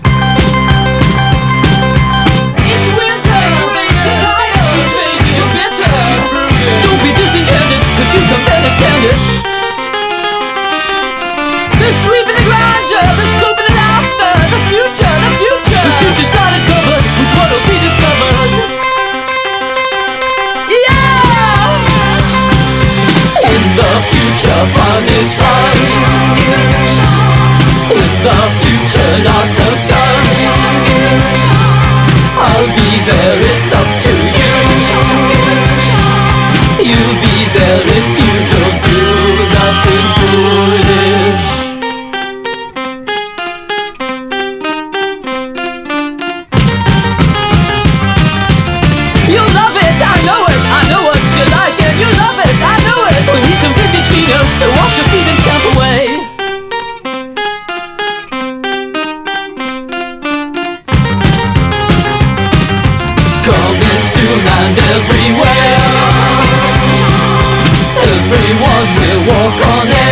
80'Sギターポップ/フォーク・ロック/カントリー・ロックの名作！
スワンピーなホーン使いが◎の